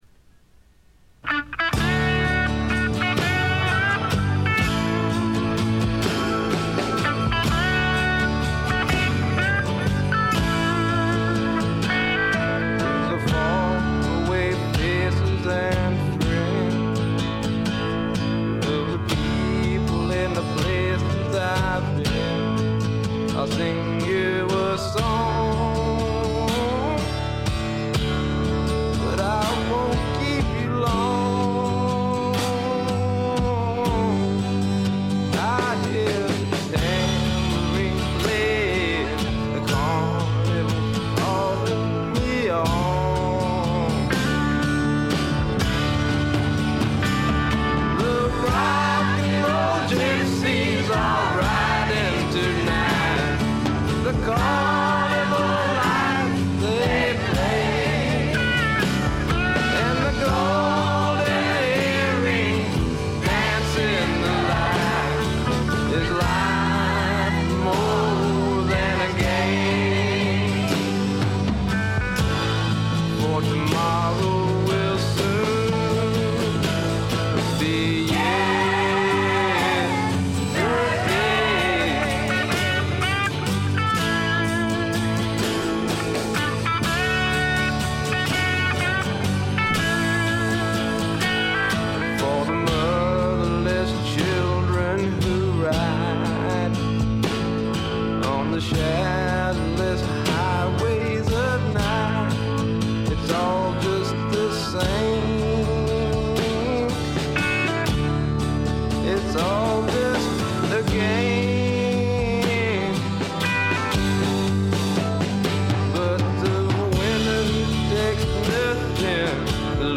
ごくわずかなノイズ感のみ。
まさしくスワンプロックの真骨頂。
試聴曲は現品からの取り込み音源です。
Vocal, Guitar, Keyboards